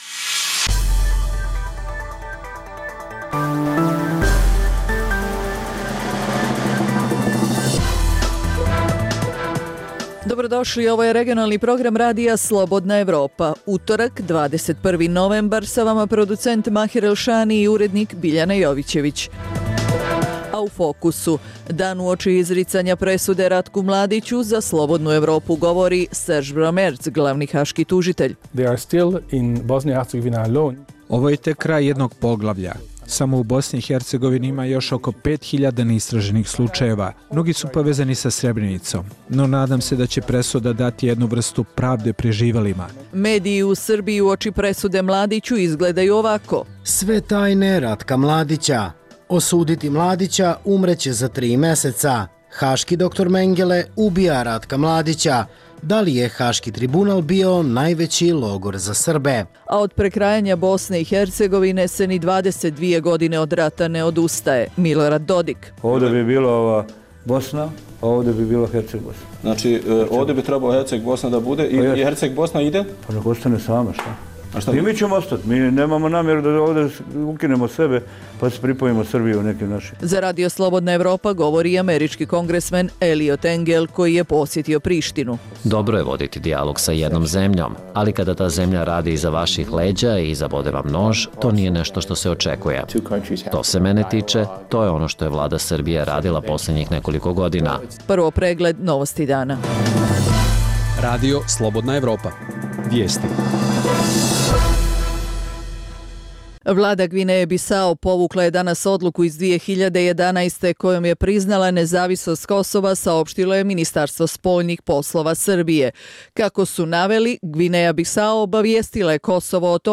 Preostalih pola sata emisije sadrži analitičke priloge iz svih zemalja regiona i iz svih oblasti, od politike i ekonomije, do kulture i sporta. Reportaže iz svakodnevnog života ljudi su svakodnevno takođe sastavni dio “Dokumenata dana”.